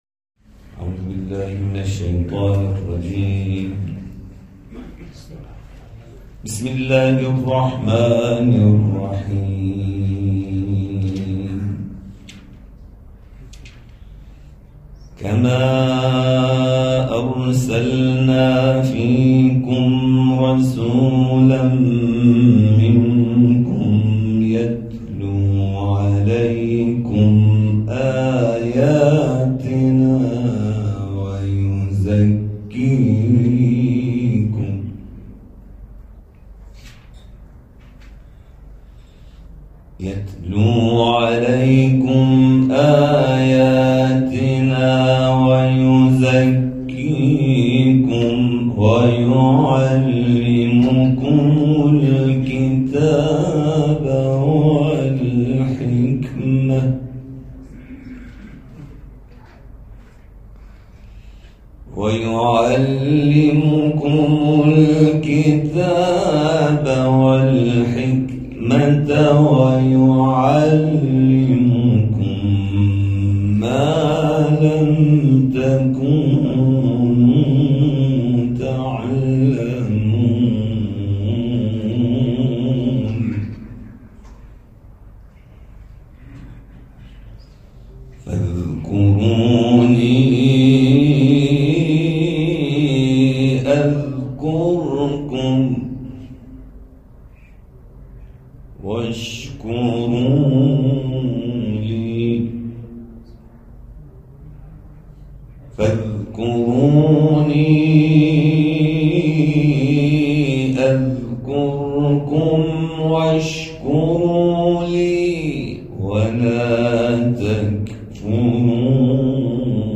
تلاوت
محفل انس با قرآن در مسجد الزهراء(س) شهرک آتی‌ساز